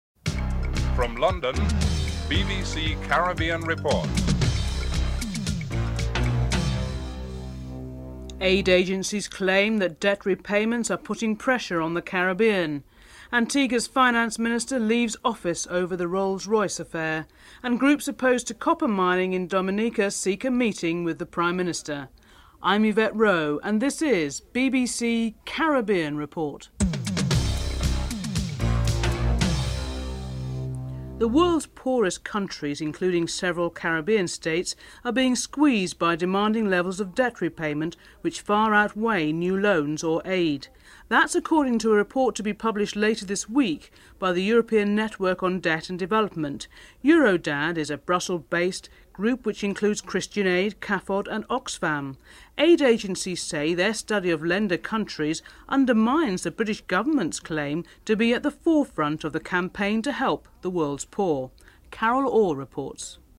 1. Headlines (00:00-00:29)
Prime Minister Vaughn Lewis is interviewed (04:02-07:42)